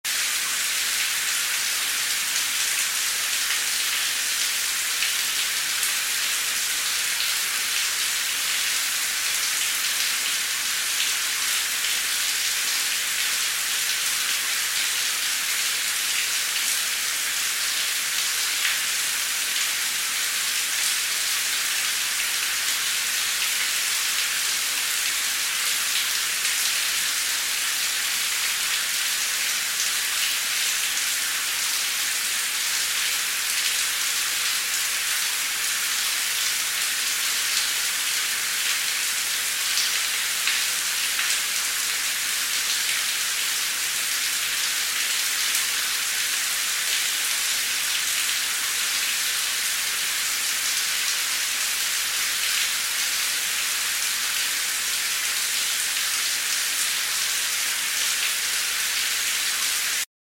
دانلود آهنگ باران 4 از افکت صوتی طبیعت و محیط
دانلود صدای باران 4 از ساعد نیوز با لینک مستقیم و کیفیت بالا
جلوه های صوتی